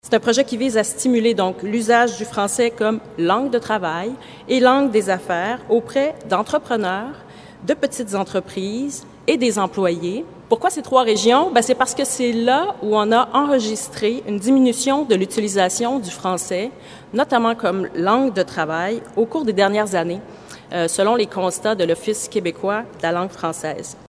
La ministre Fréchette apporte plus de précision :